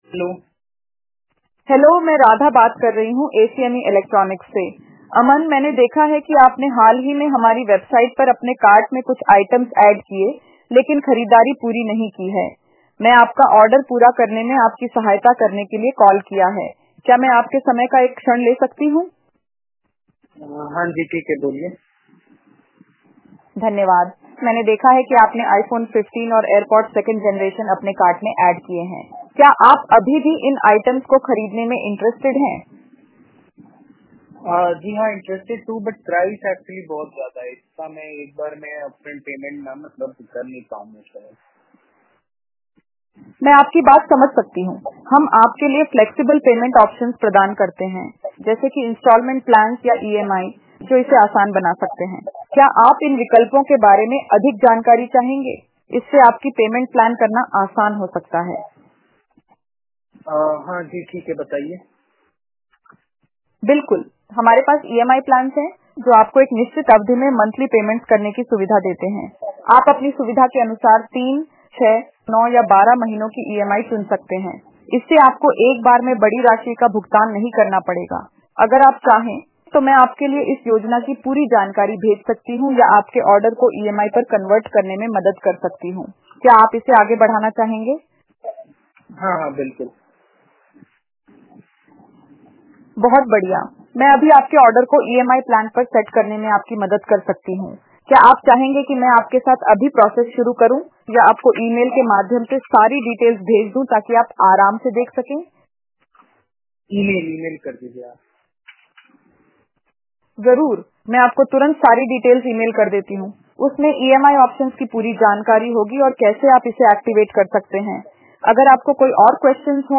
See our AI in action